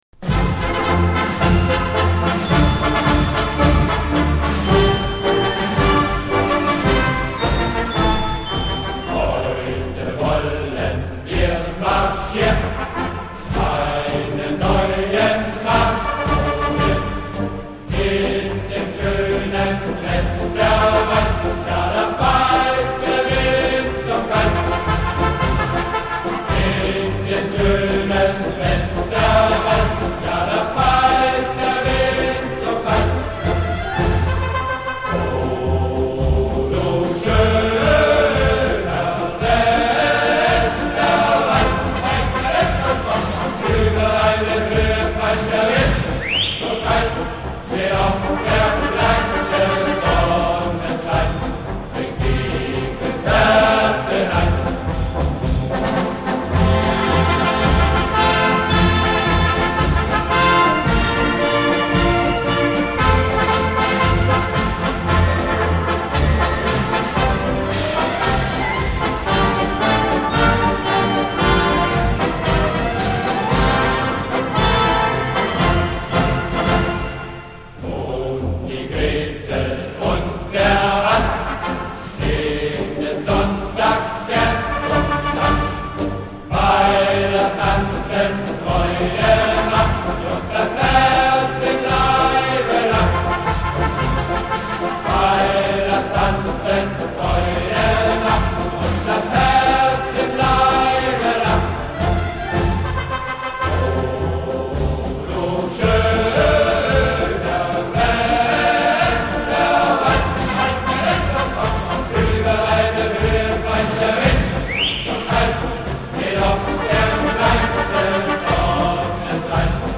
一首比一首振奋人心，一首一首，节奏更强劲